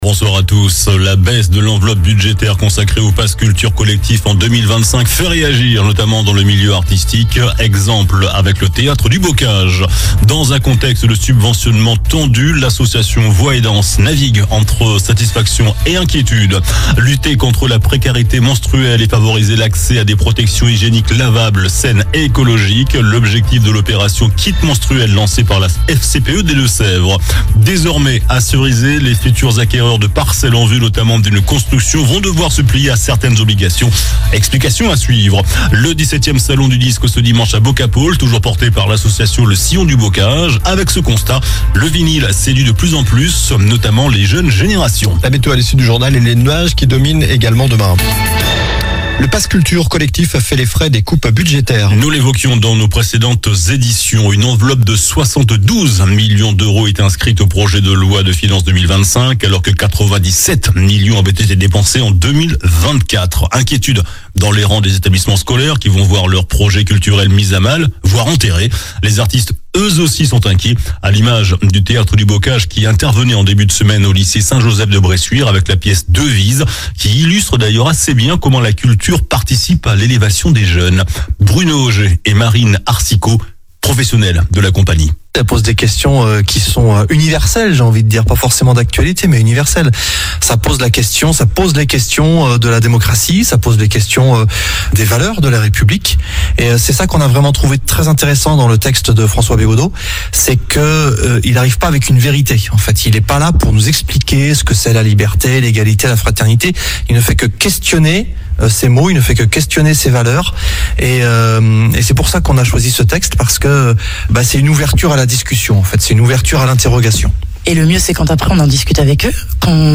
JOURNAL DU MERCREDI 05 FEVRIER ( SOIR )